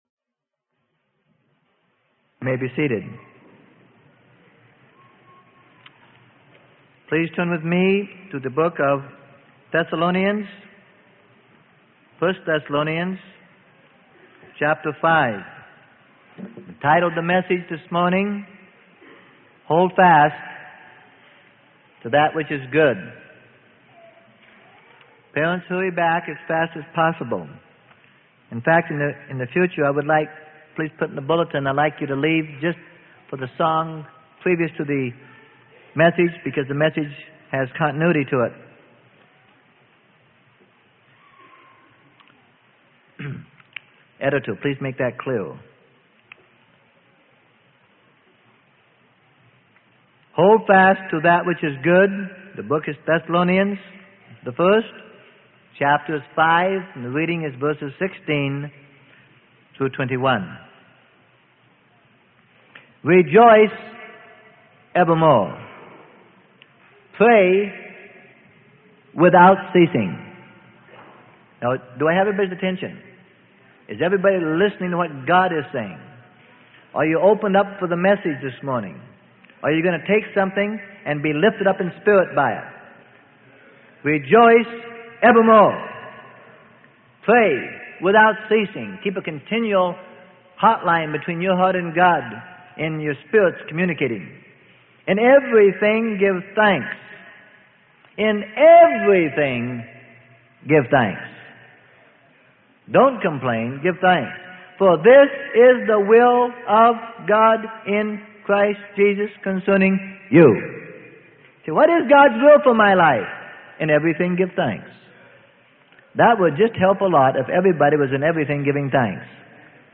Sermon: Hold Fast to That Which is Good - Freely Given Online Library